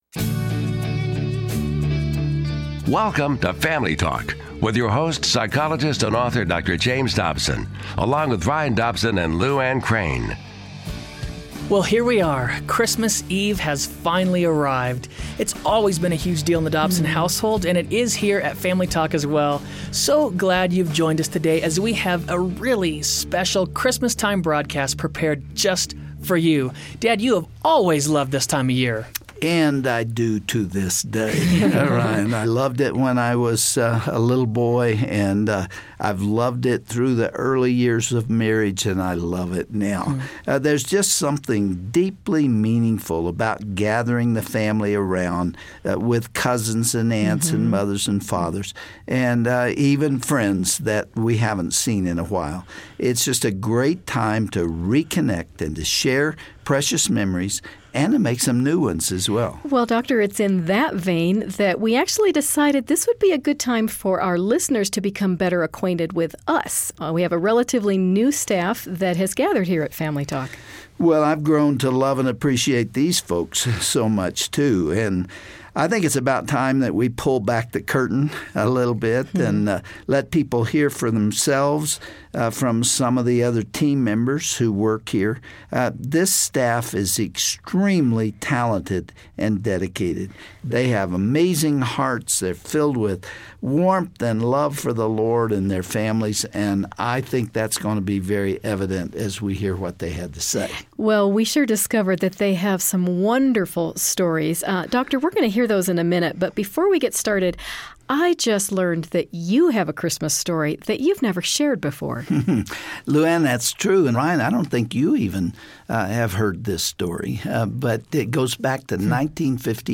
Get to know the wonderful folks behind the scenes as Family Talk staff members reveal their favorite Christmas memories. Learn who spray painted their house with fake snow in order to have a white Christmas!